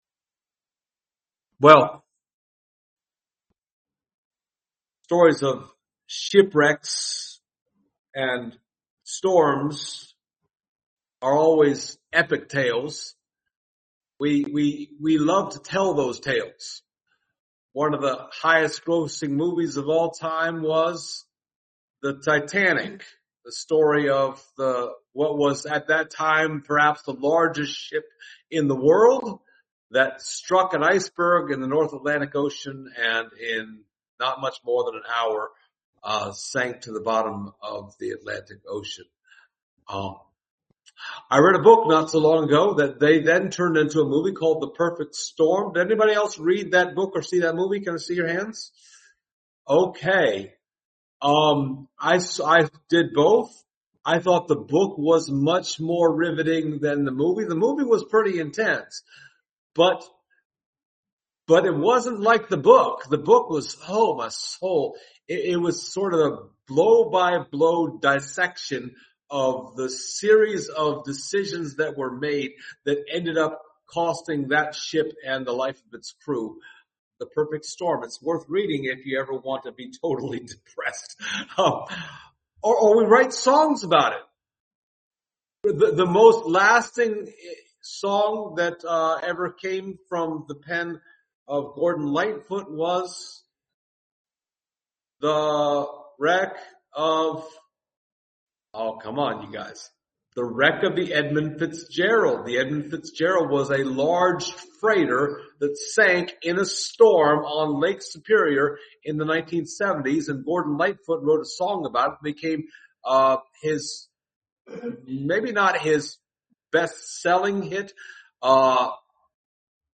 The Acts of the Holy Spirit Passage: Acts 27 Service Type: Sunday Morning Topics